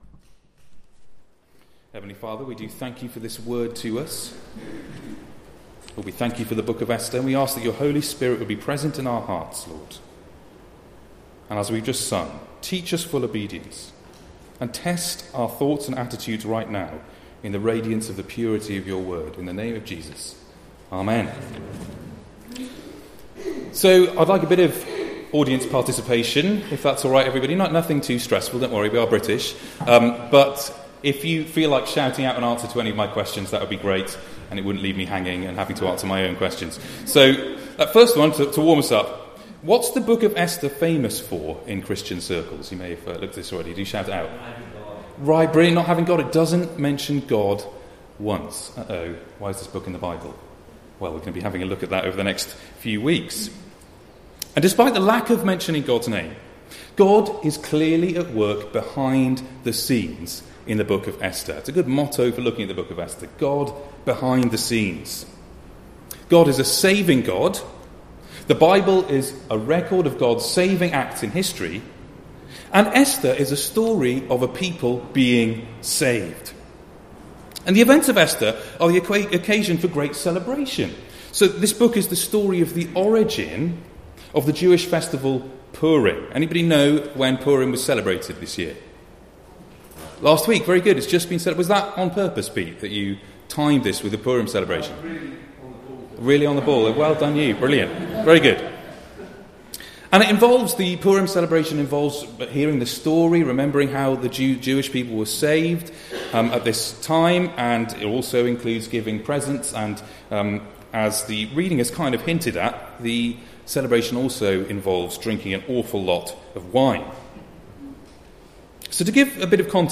Passage: Esther 1: 1-22 Service Type: Weekly Service at 4pm